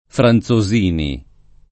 [ fran Z o @& ni ]